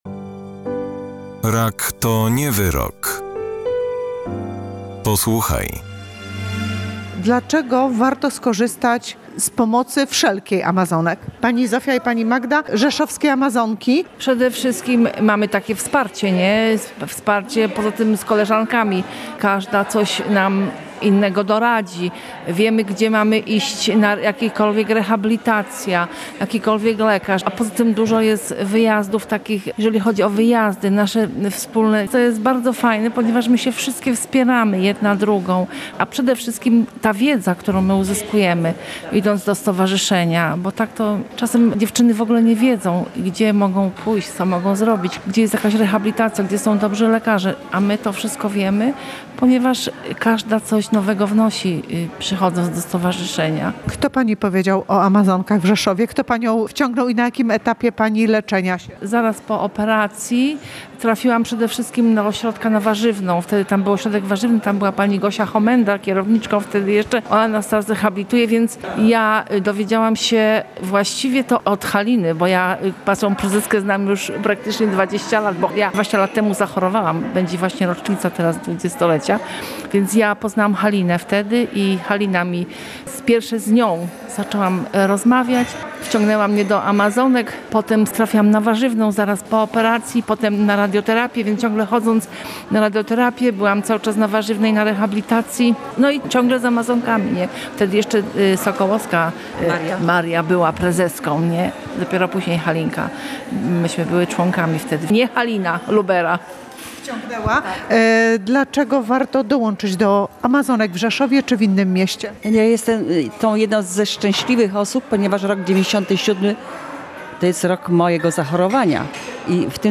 rozmawia z paniami z Stowarzyszenia „Rzeszowski Klub Amazonka”.